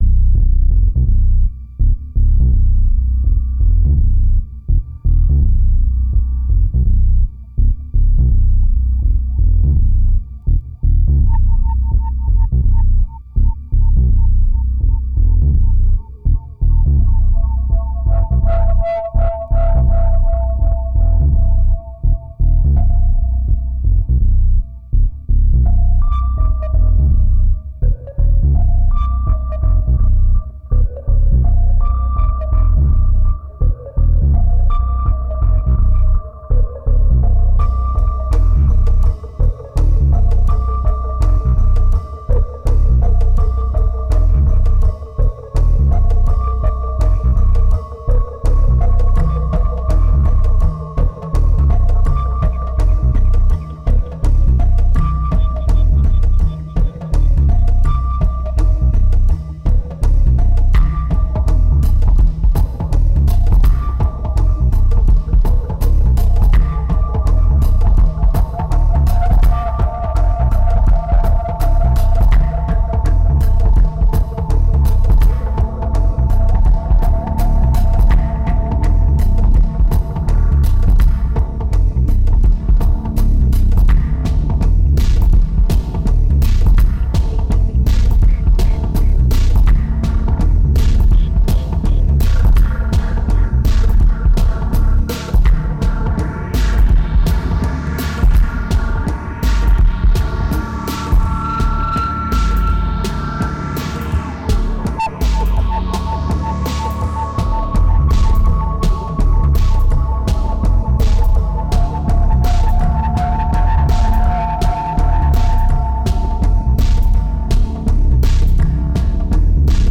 2147📈 - -59%🤔 - 83BPM🔊 - 2010-06-24📅 - -371🌟